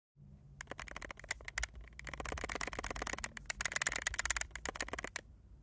Google Pixel 7 Pro klappert
Äußerlich scheint alles in Ordnung zu sein, allerdings klappert das Telefon stark, sobald man es bewegt oder schüttelt.
Ich habe noch eine Audiodatei aus der Rekorder App vom betroffenen Pixel angehangen, dort ist das Klappern deutlich hörbar.